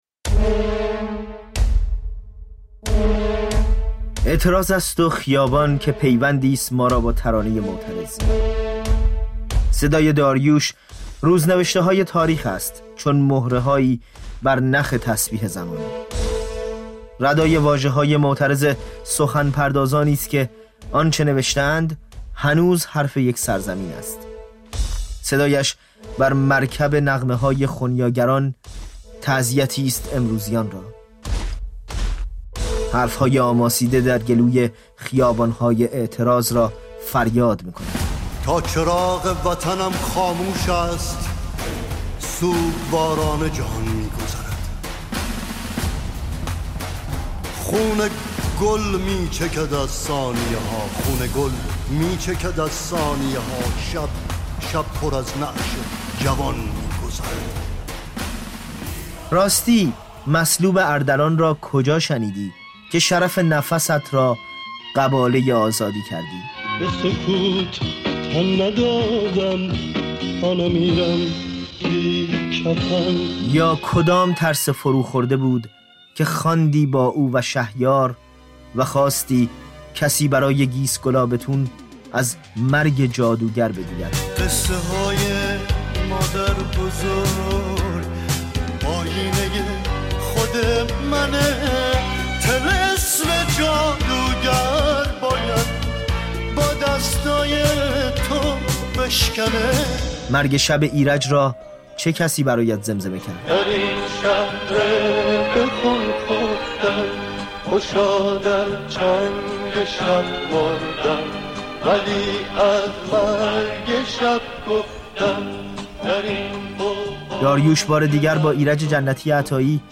با این دو هنرمند گفت‌وگو کرده است: